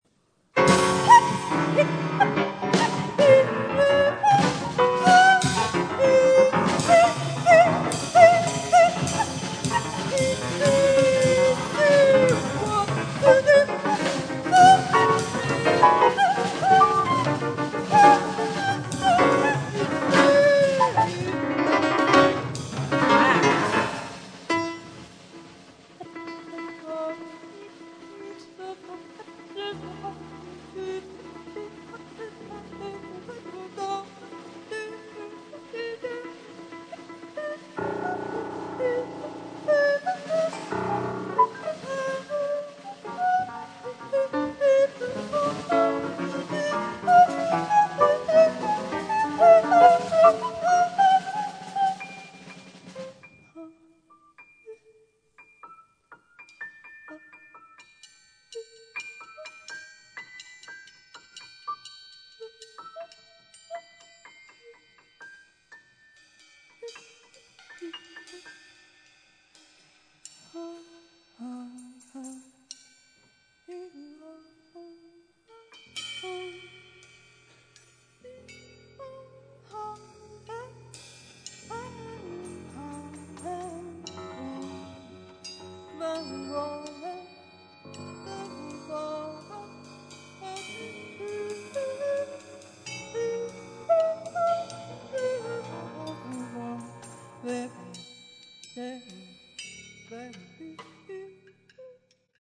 piano
drums, live in Borjomi, Georgia.